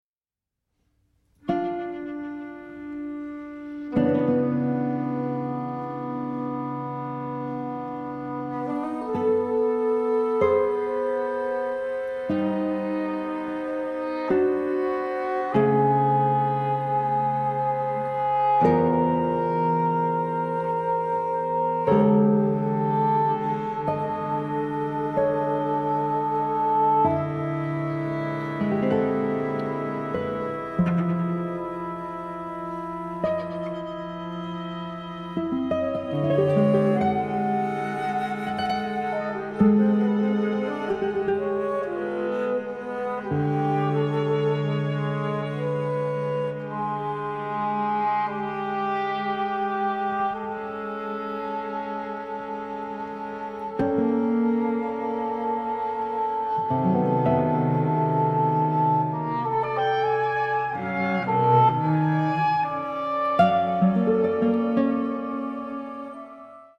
chamber works for various instrumentations